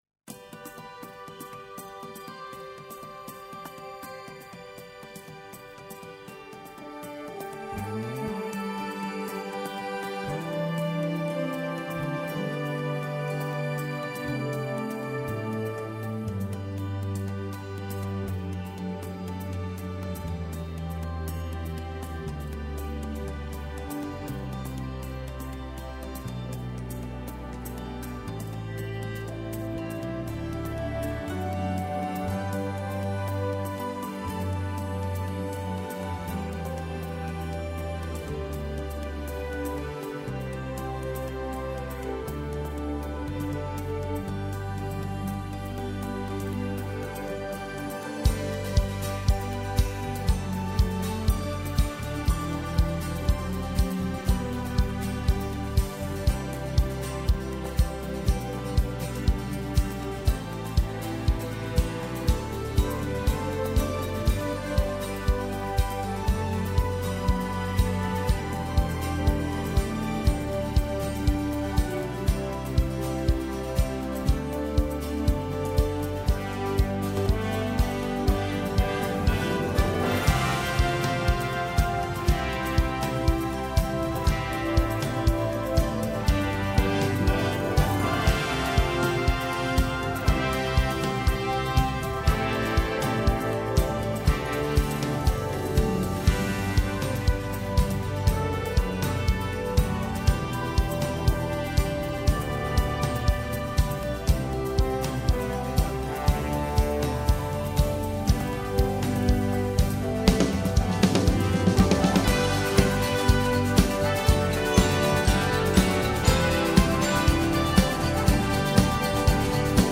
Download Back Track MP3